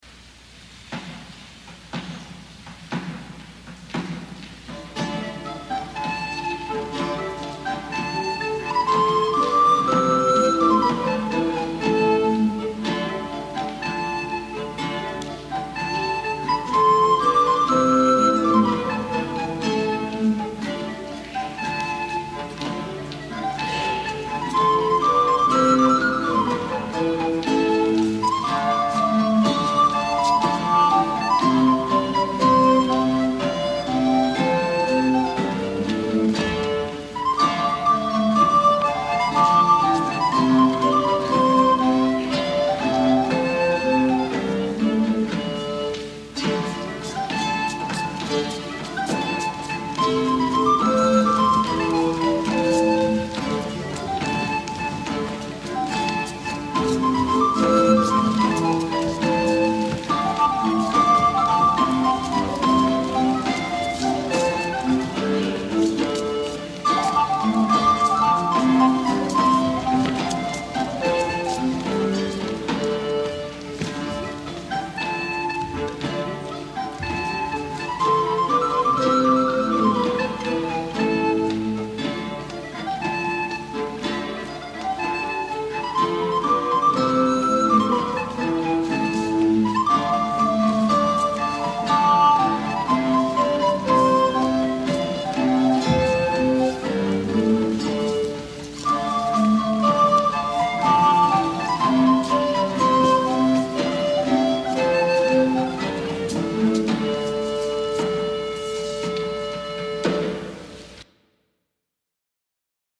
Dance Ensemble Instruments
recorder